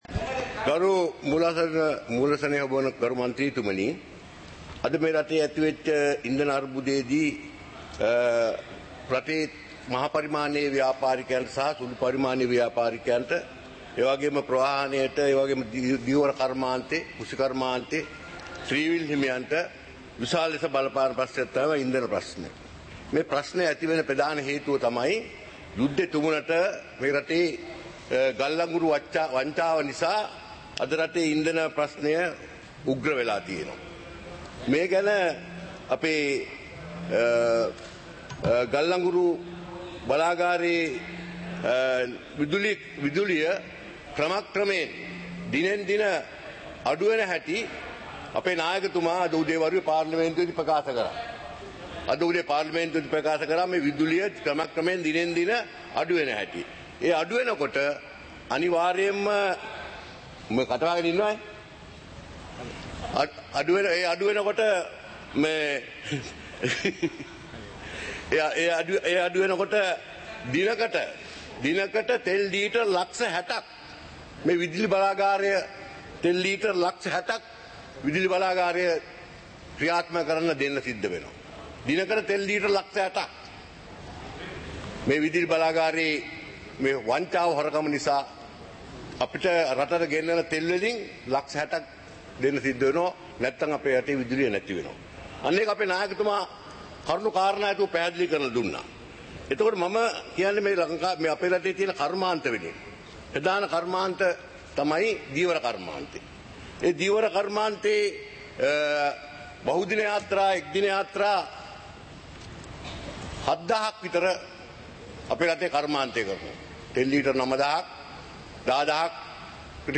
சபை நடவடிக்கைமுறை (2026-03-20)